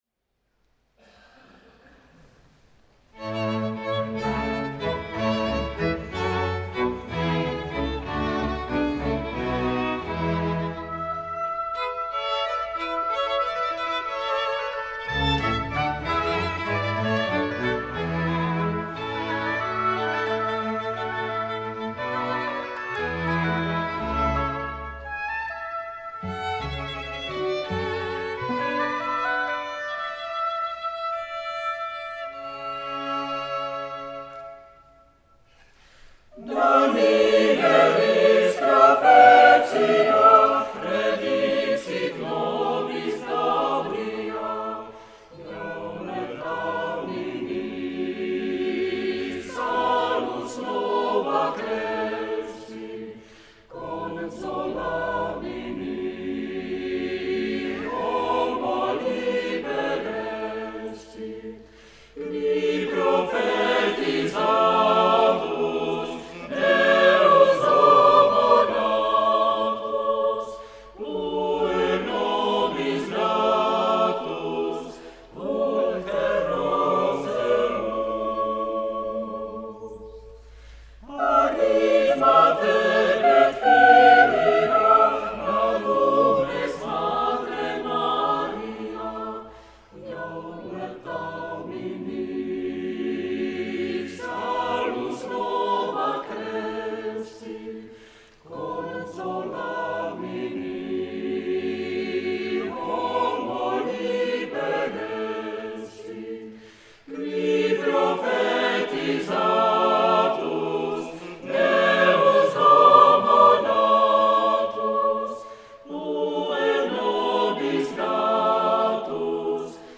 The list origins from concerts performed from 1971.